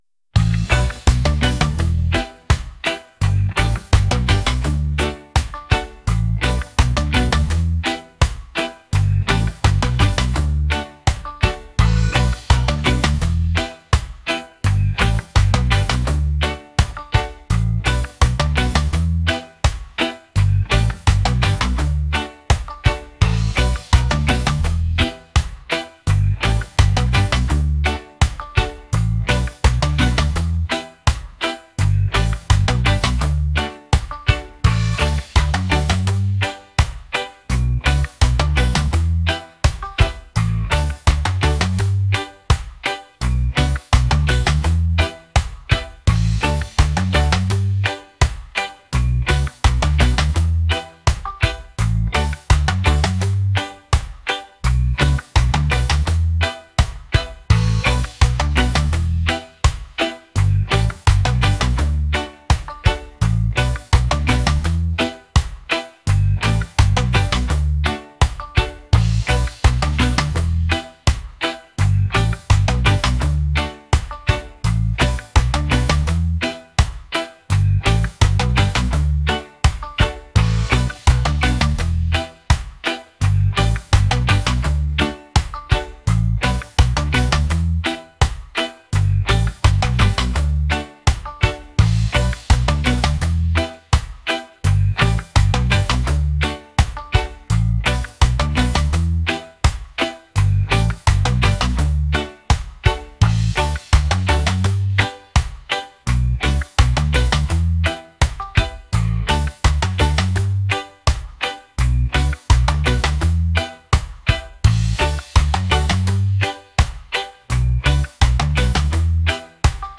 reggae | upbeat | catchy